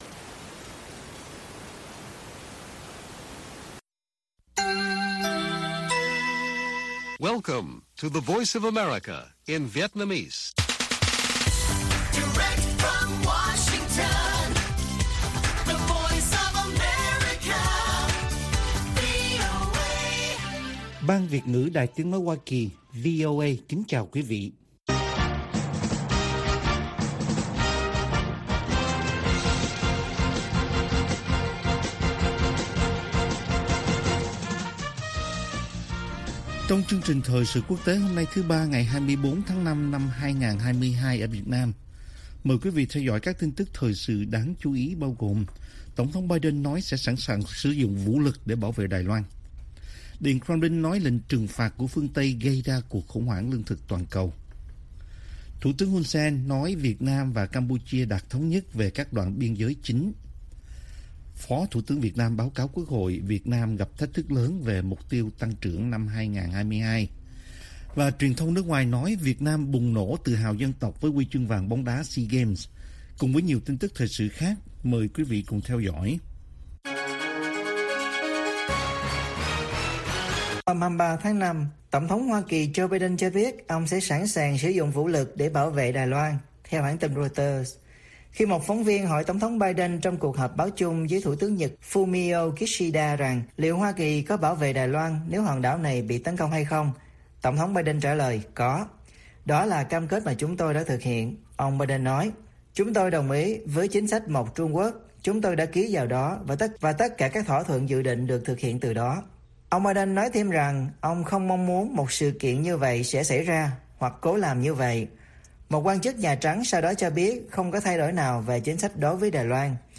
TT Biden nói sẽ sẵn sàng sử dụng vũ lực để bảo vệ Đài Loan - Bản tin VOA